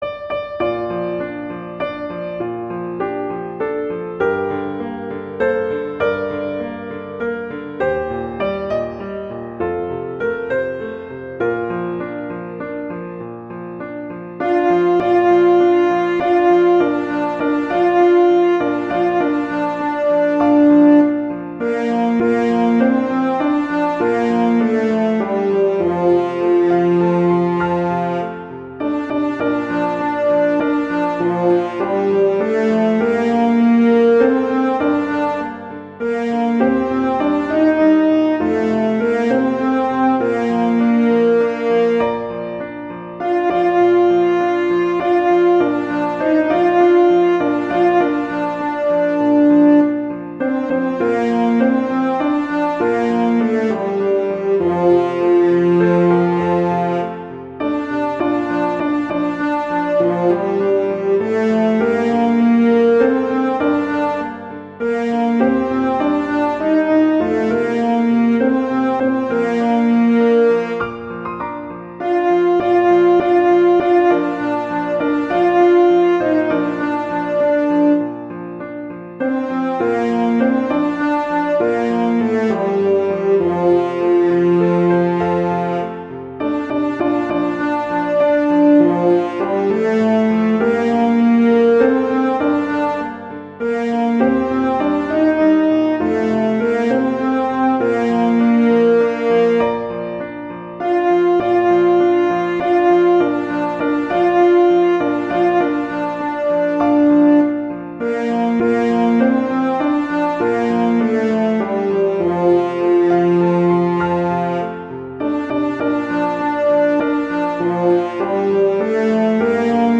arrangements for horn and piano
traditional, irish, children